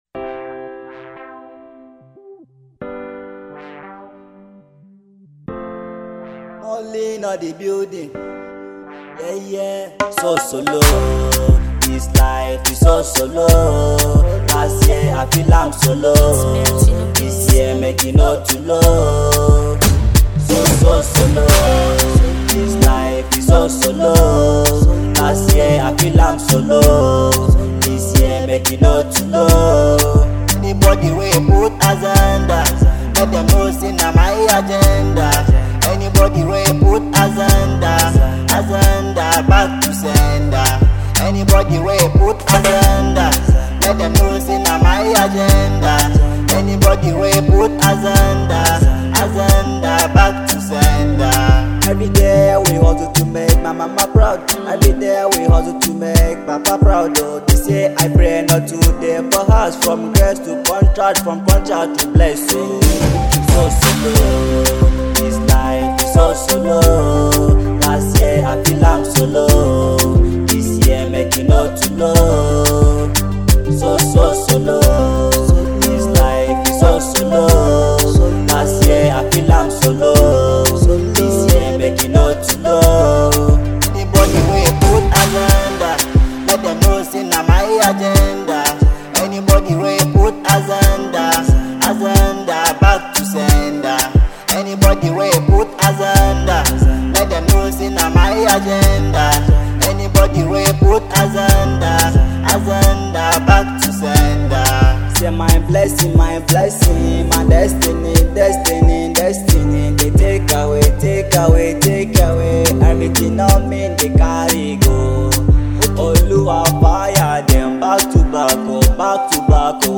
song of joy